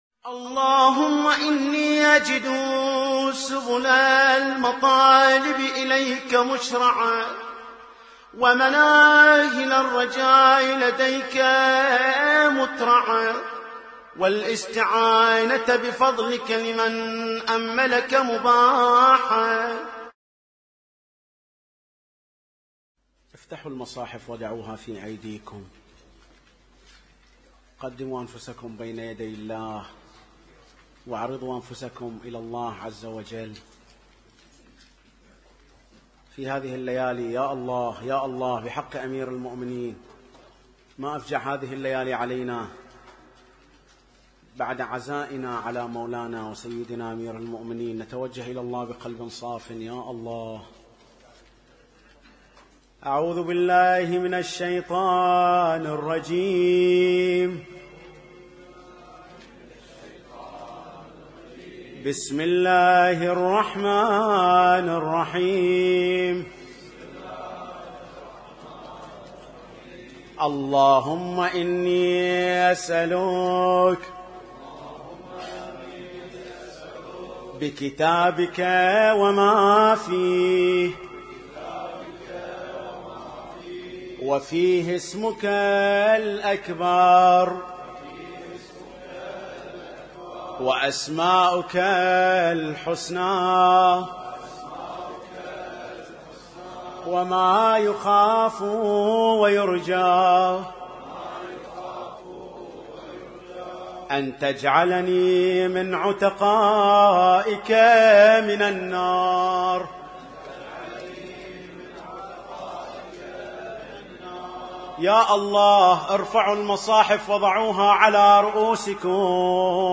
اسم التصنيف: المـكتبة الصــوتيه >> الادعية >> ادعية ليالي القدر